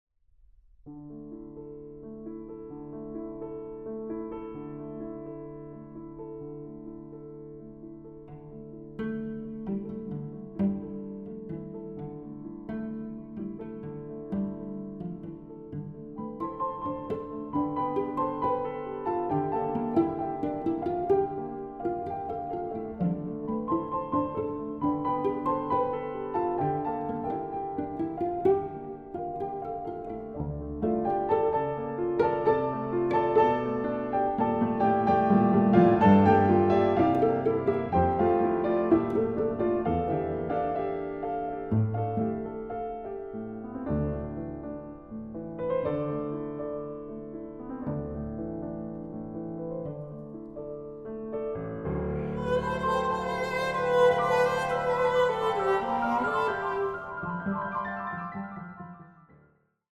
Violoncello
Klavier
Sonate für Violoncello und Klavier